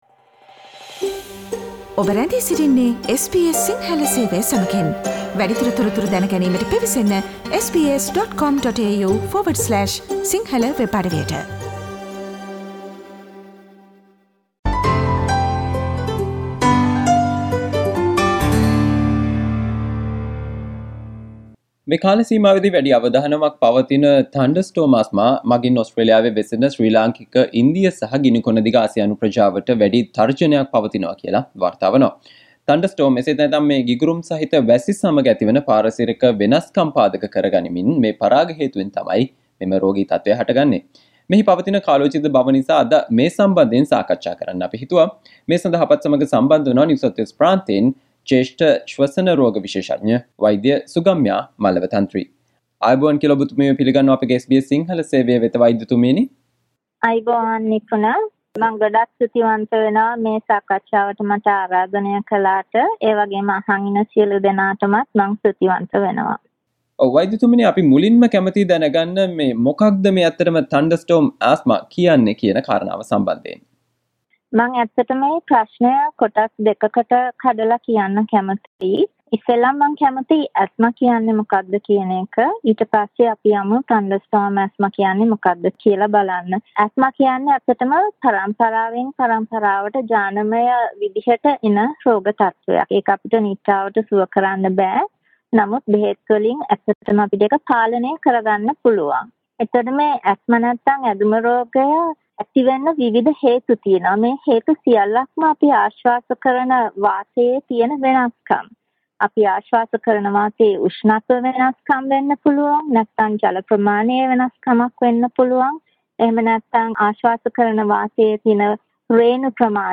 SBS සිංහල සේවය සිදු කල සාකච්චාව